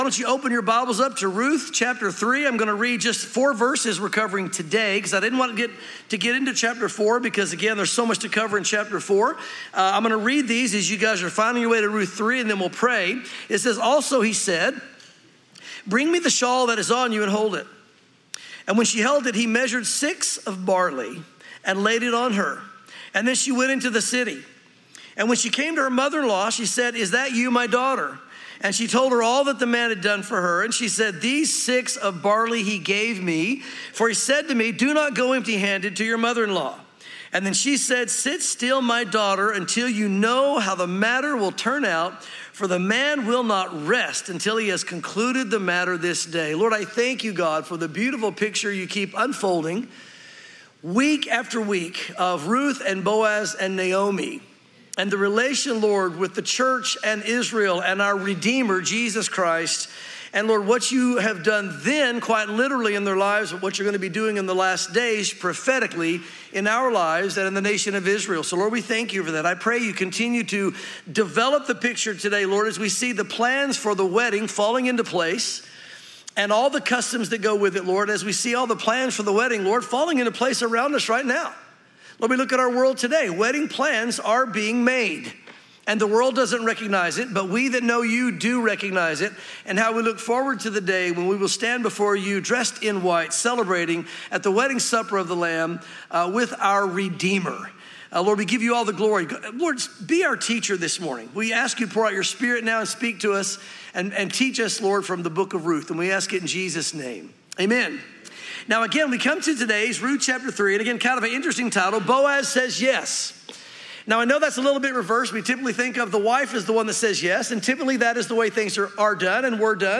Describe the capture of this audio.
Welcome to Calvary Chapel Knoxville!